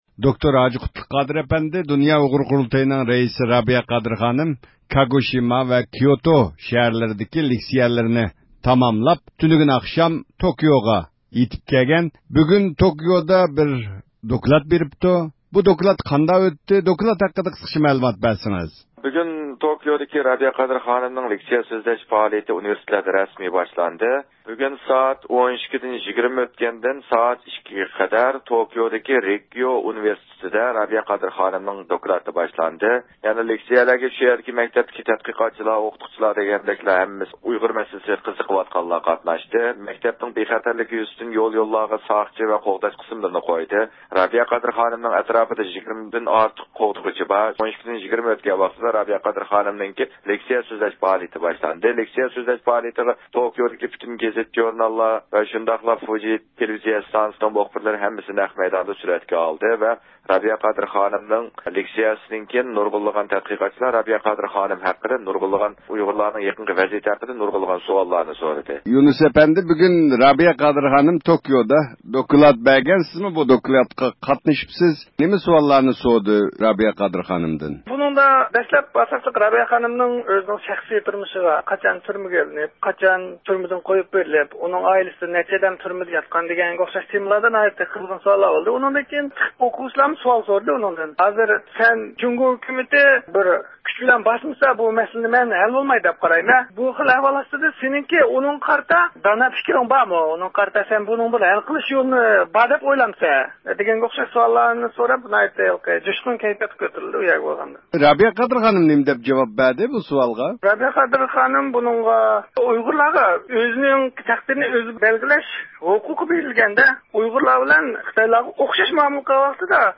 رابىيە قادىر خانىم، بۇ قېتىمقى دوكلاتىدا نوختىلىق ھالدا ئۇيغۇر مەسىلىسىنى ھەل قىلىشنىڭ يوللىرى ئۈستىدە توختالغان. بىز بۇ ھەقتە تەپسىلىي مەلۇمات ئېلىش ئۈچۈن رابىيە قادىر خانىم ۋە بۇ دوكلاتنى ئاڭلىغان ياپونىيىدىكى ئۇيغۇرلار بىلەن تېلېفون سۆھبىتى ئېلىپ باردۇق.